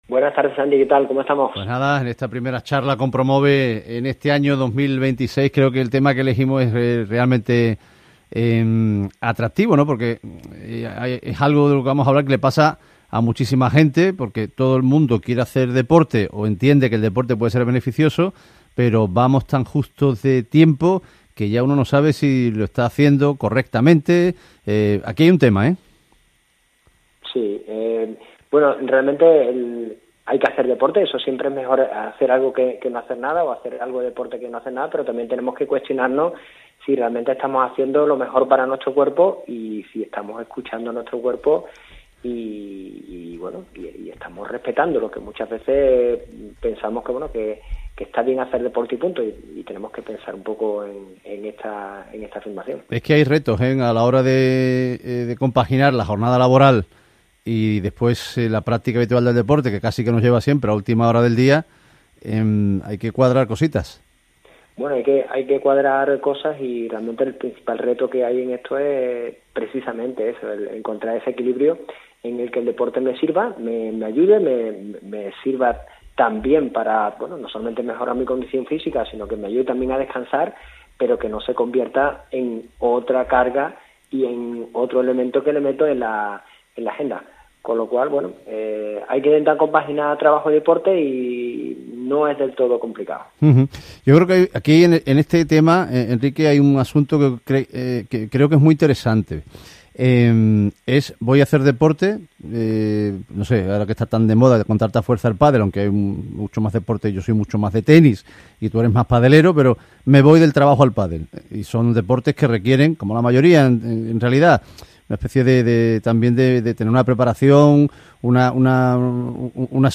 entrevista completa